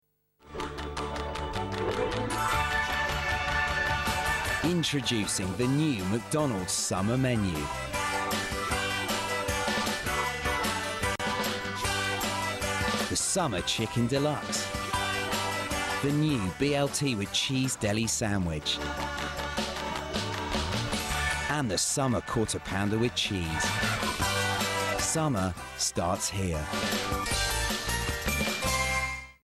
Friendly, natural & easy going voice with a London edge. From upbeat hard sell to laid back & conversational, perfect for ads, promos and narrations.